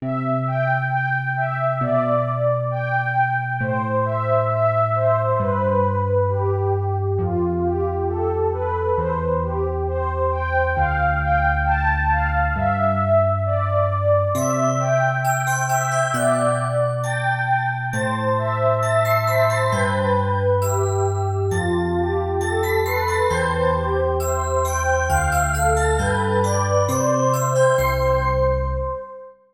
優しいPadとBellが母性の優しさをメロディに乗せて歌います。
ループ